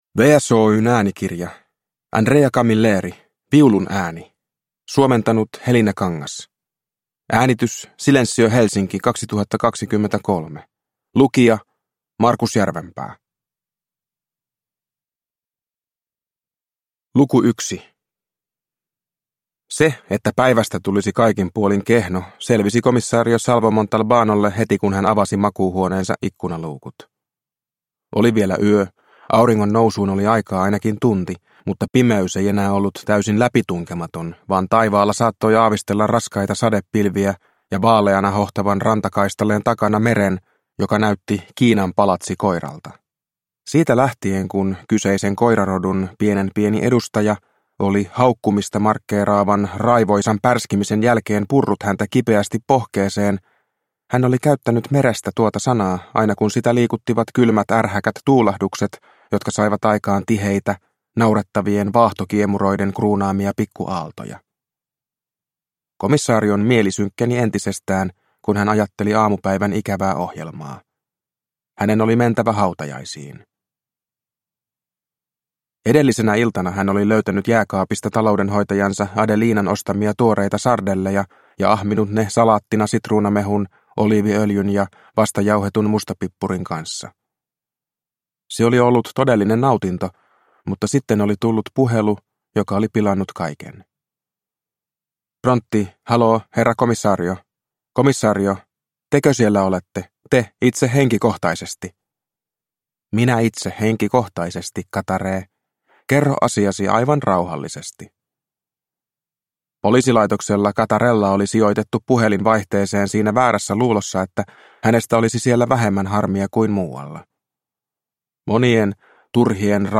Viulun ääni – Ljudbok